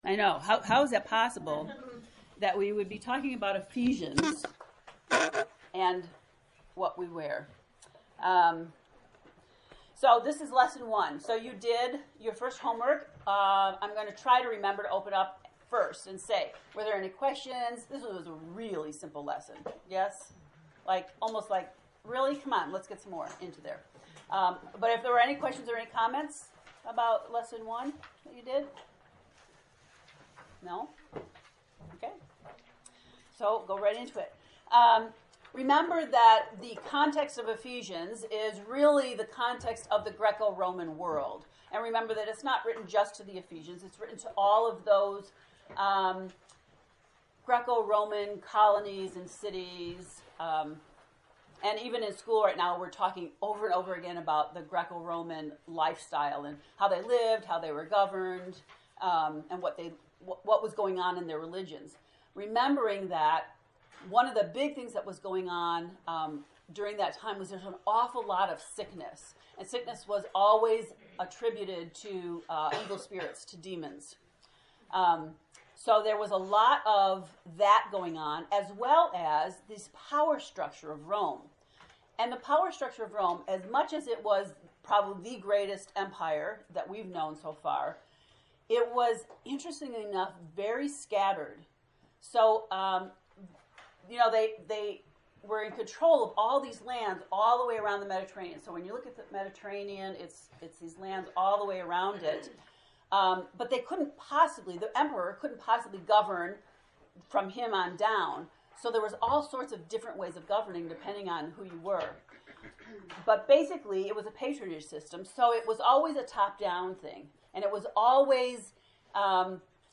To listen to the lecture from lesson 1, “The Power of Presence”, please click below: